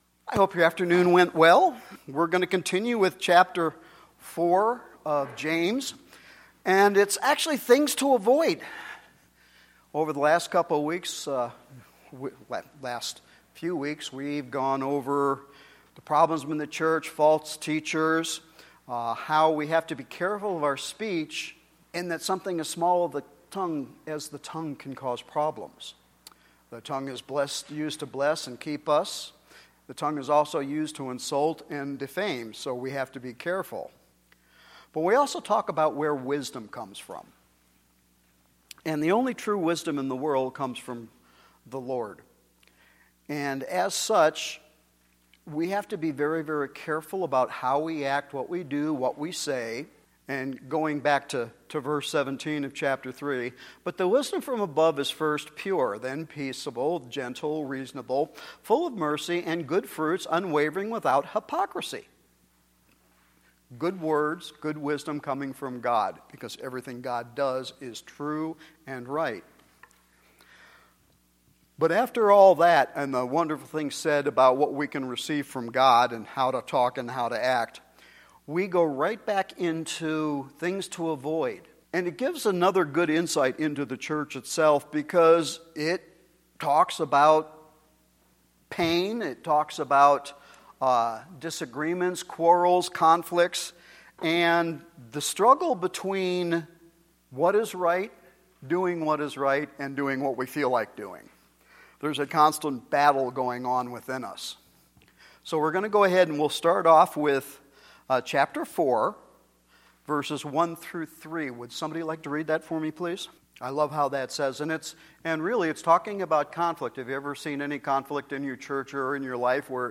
Sunday Evening Bible Study James 4:1-12